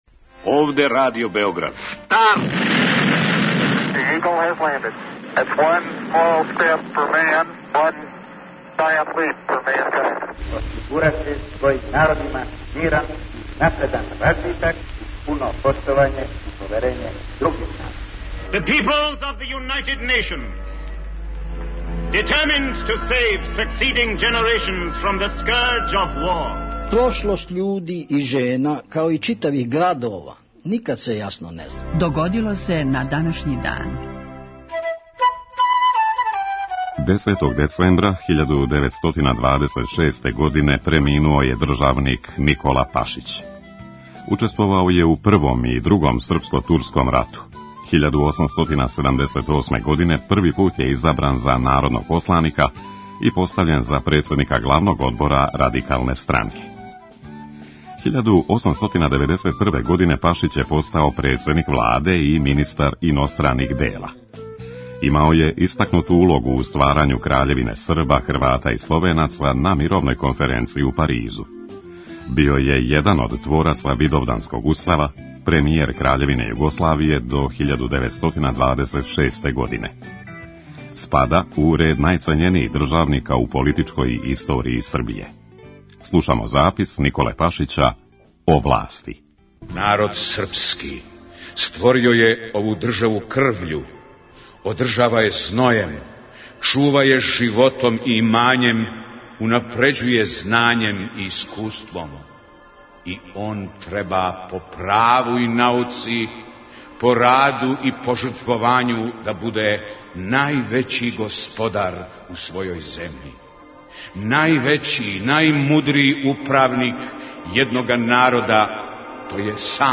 Емисија Догодило се на данашњи дан, једна од најстаријих емисија Радио Београда свакодневни је подсетник на људе и догађаје из наше и светске историје. У петотоминутном прегледу, враћамо се у прошлост и слушамо гласове људи из других епоха.